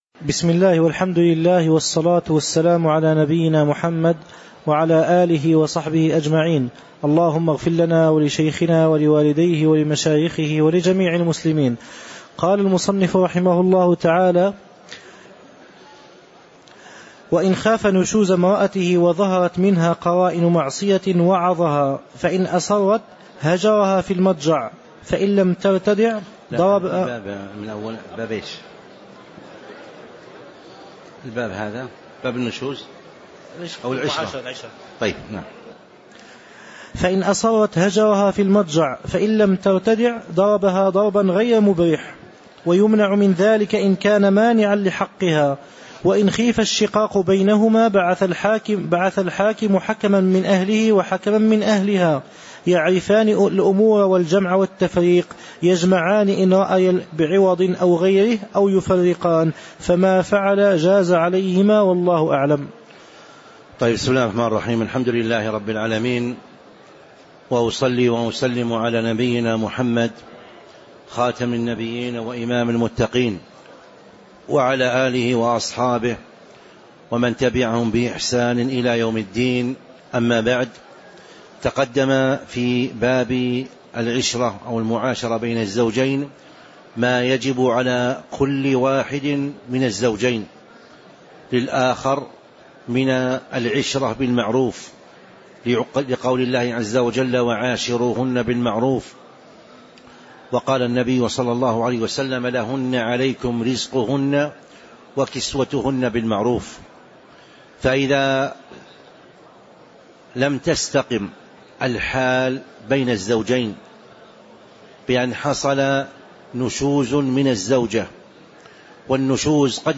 تاريخ النشر ٢٦ رجب ١٤٤٦ هـ المكان: المسجد النبوي الشيخ